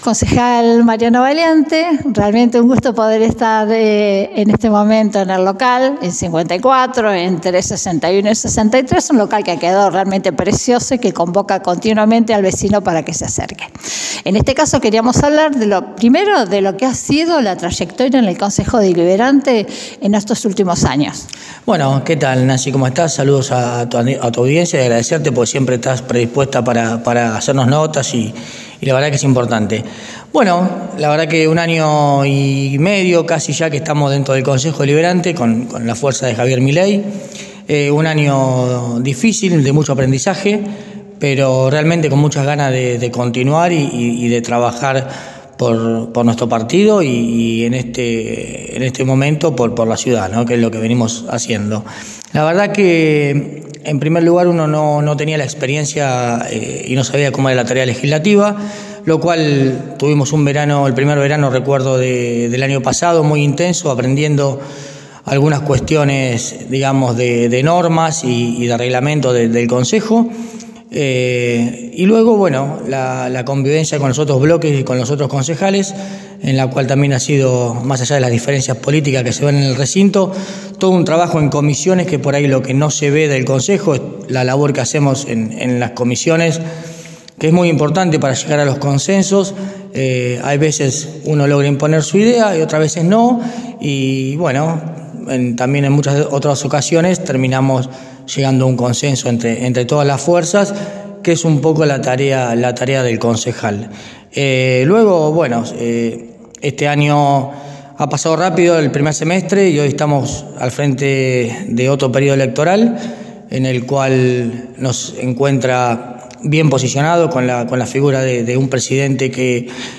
En diálogo con Mariano Valiante, concejal por LLA y candidato a Senador Provincial | Aires de la Ciudad
En el día de ayer realizamos una visita al local de La Libertad Avanza, donde pudimos observar el movimiento que se viene llevando con vistas a las próximas elecciones legislativas del 7 de septiembre.